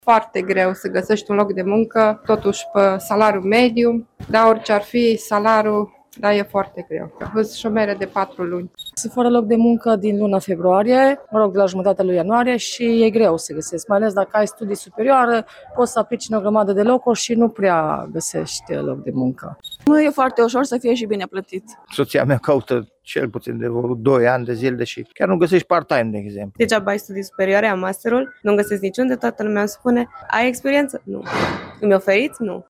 „Este foarte greu să găsesc un loc de muncă, totuși, pe salariul mediu”, a spus o arădeancă